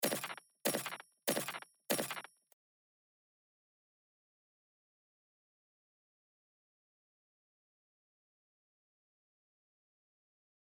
granular_01：サンプルを読み込んだ直後の状態。
再生してみると読み込んだサンプルがループ再生されているだけですが、ここで“SPRAY”の数値を大きくしてみましょう。
granular_01.mp3